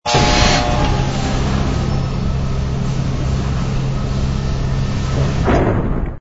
door_large_close.wav